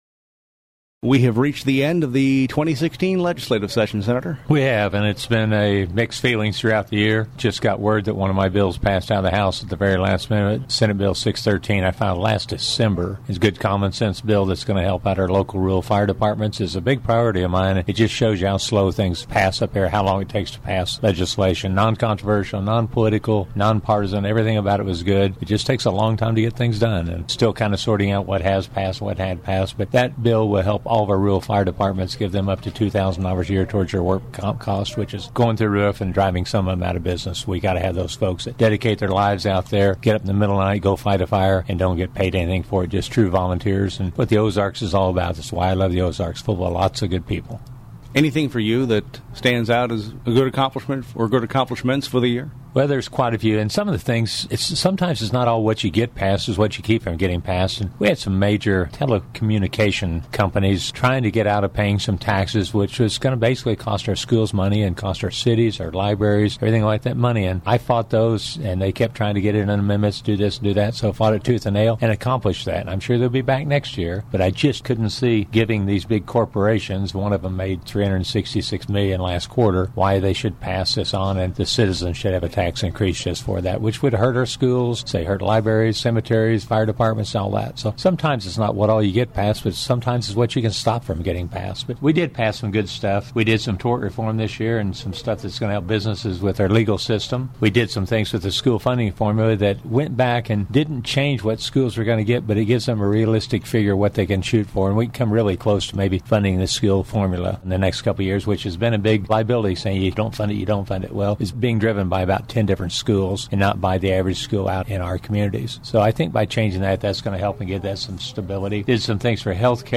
The audio below is a full-length interview with Sen. Cunningham — also available via podcast — for the week of May 9, 2016.